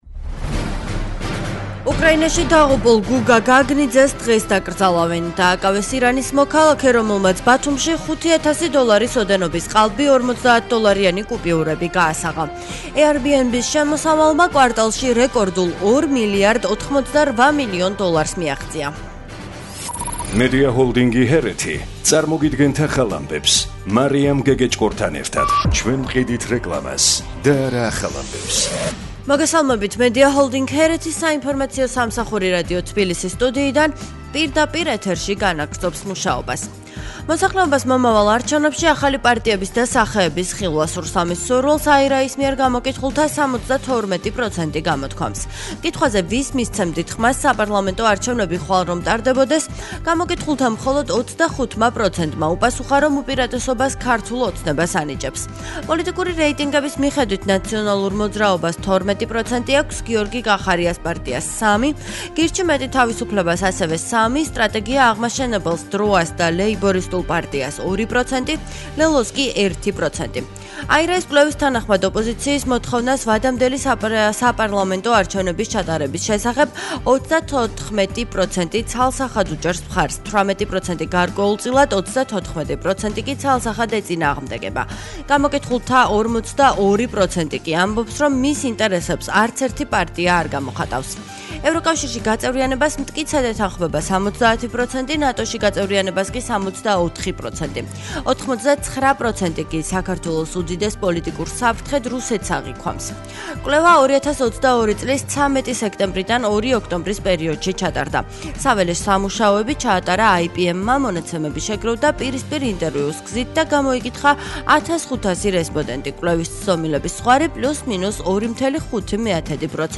ახალი ამბები 11:00 საათზე